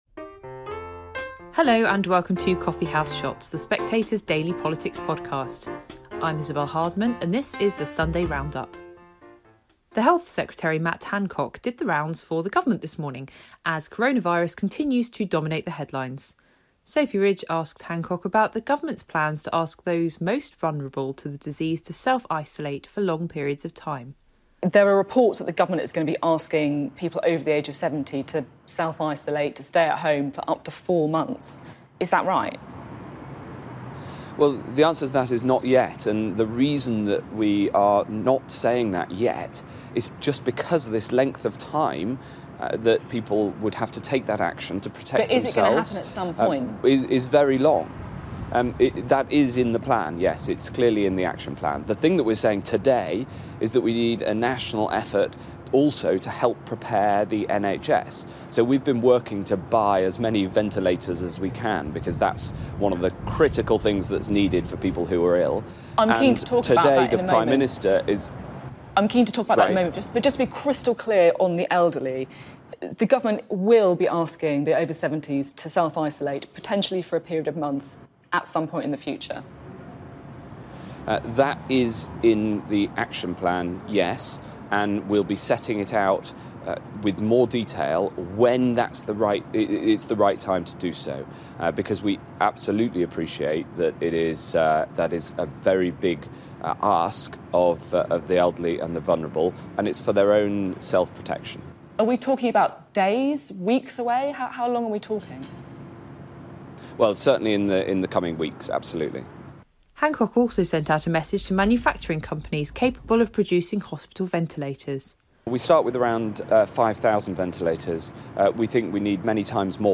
Isabel Hardman hosts the highlights from today's political shows, featuring Matt Hancock, Jonathan Ashworth, Lisa Nandy and Italian Ambassador Raffaele Trombetta.